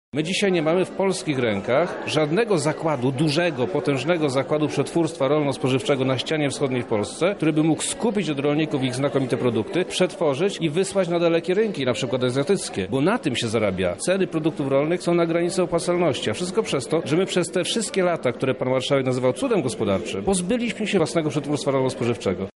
Instytut Ekonomii i Zarządzania KUL oraz Oddział Regionalny KRUS zorganizował debatę „Zmiany na polskiej i lubelskiej wsi po roku 1990 – rola instytucji otoczenia rolnictwa”.
Polska wieś ma dwa problemy, pierwszy to demografia, a druga to przetwórstwo – mówi wojewoda lubelski Przemysław Czarnek: